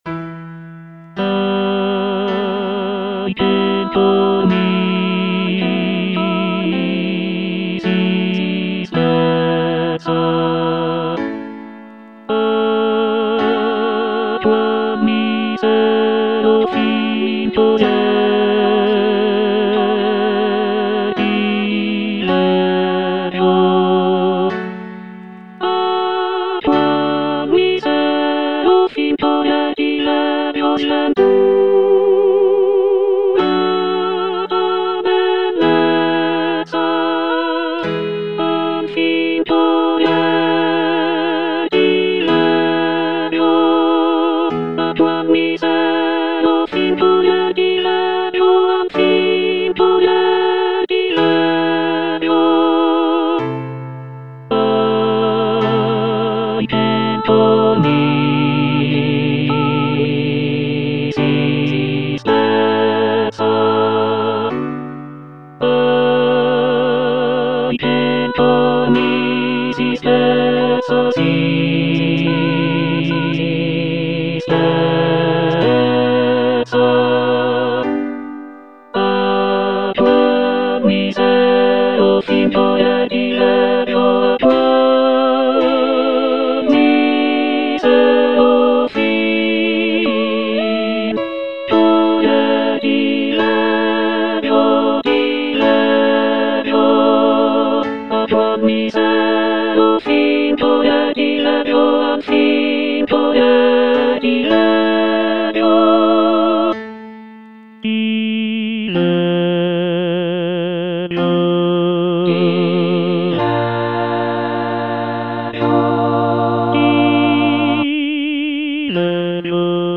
Choralplayer playing Lamento d'Arianna (version 2) by C. Monteverdi based on the edition Bärenreiter BA 3368
C. MONTEVERDI - LAMENTO D'ARIANNA (VERSION 2) Coro II: Ahi! che'l cor mi si spezza - Tenor (Emphasised voice and other voices) Ads stop: auto-stop